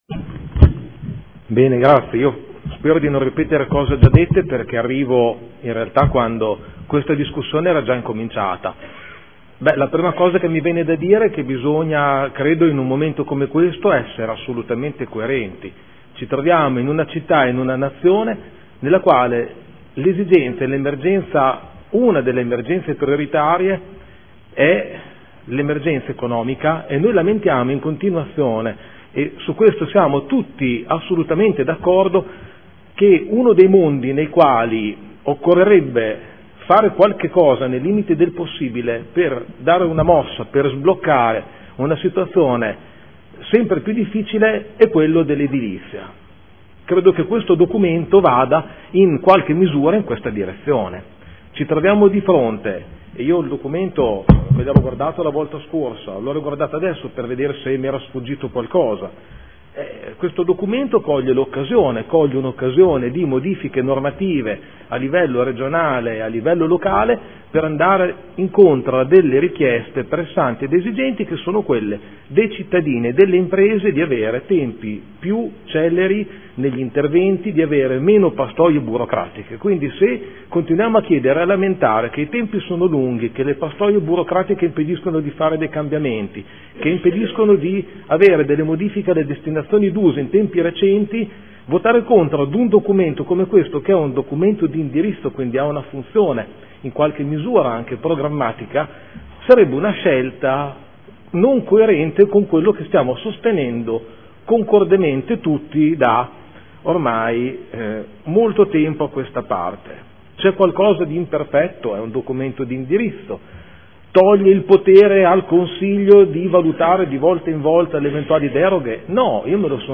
Giuseppe Pellacani — Sito Audio Consiglio Comunale